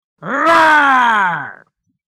Man_roaring,_high_qu.mp3